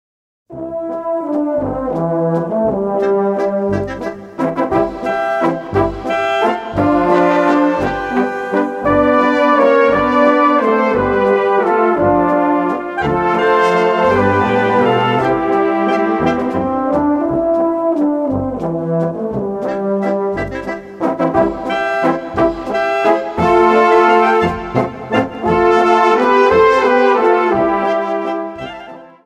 Gattung: Walzer
A-B Besetzung: Blasorchester Zu hören auf